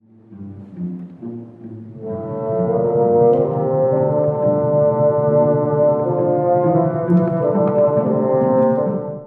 この交響曲の「暗闇との葛藤」を最も強く表している楽章です。